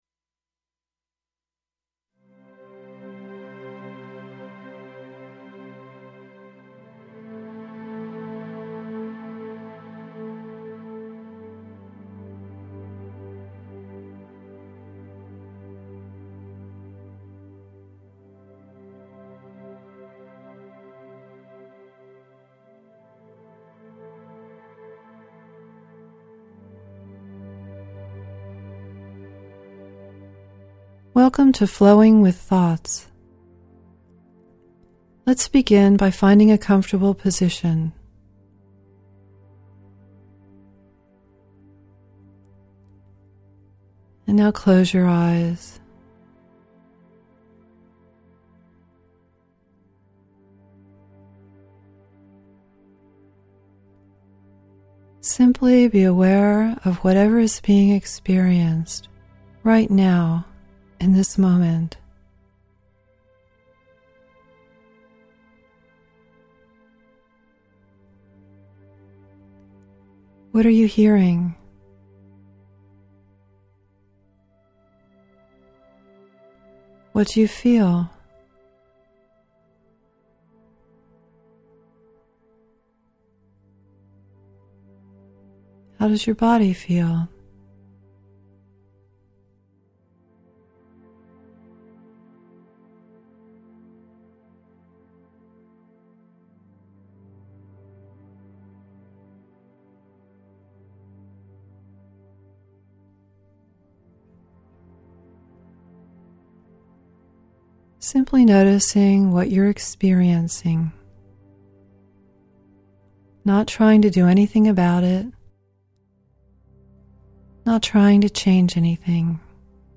Introduction to "Flowing with Thoughts" Guided Meditation
How to Listen to the Guided Meditation You will be voice-guided for 15 minutes, and then encouraged to continue on your own for 5 minutes more.
3a-thoughts-with_music.mp3